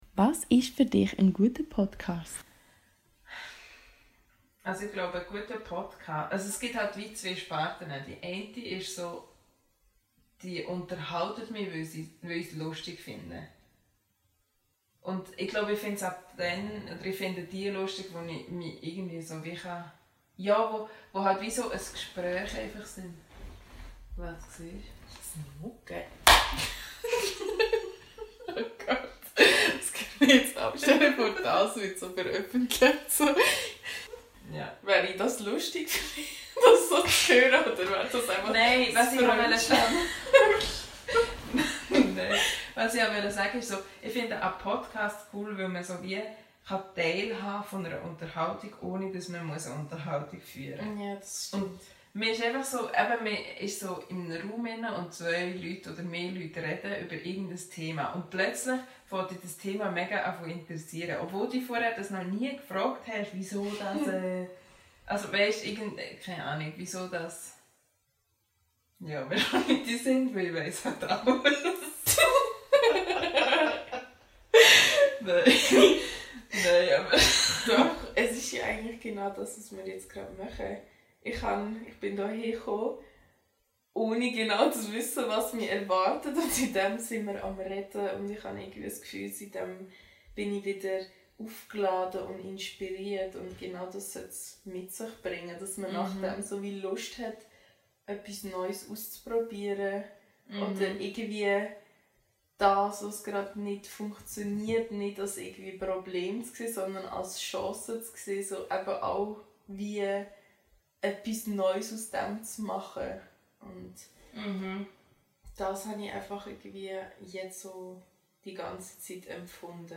Nun wir haben diese Folge mit dem Handy gestern Nacht aufgezeichnet und heute Morgen das Logo auf der...